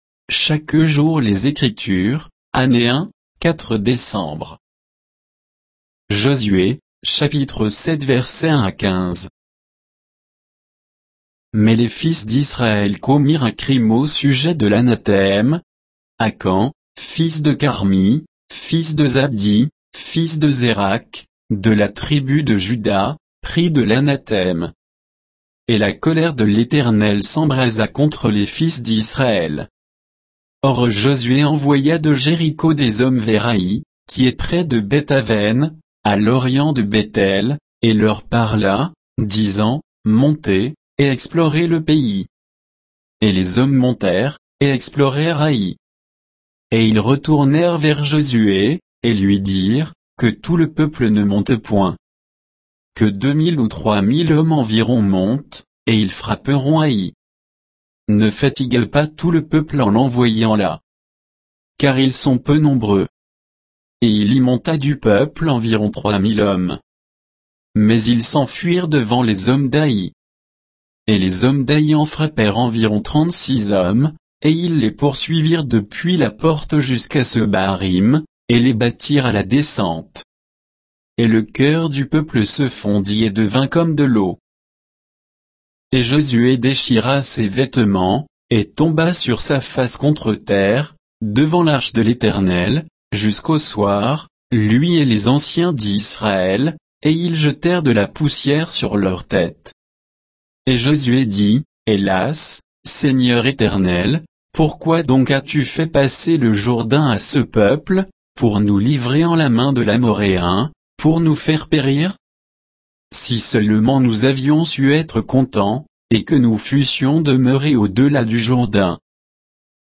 Méditation quoditienne de Chaque jour les Écritures sur Josué 7, 1 à 15